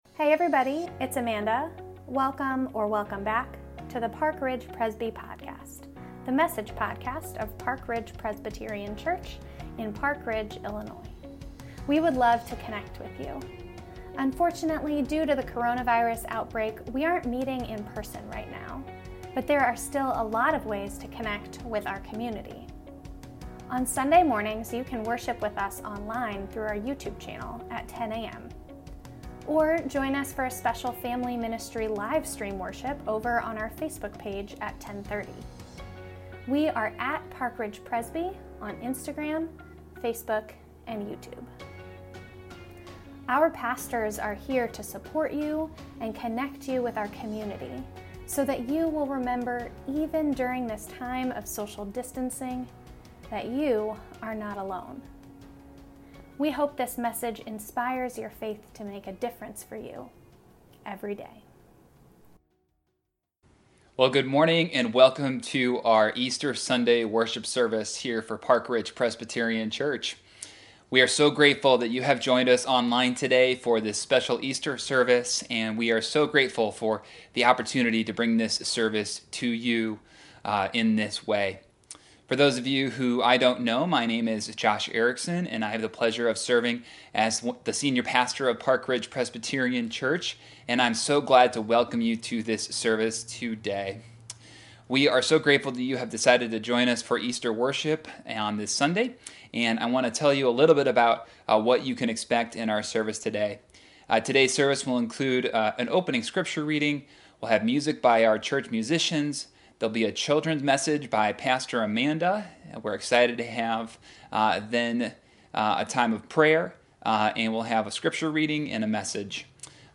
The 2020 Easter Sunday Service!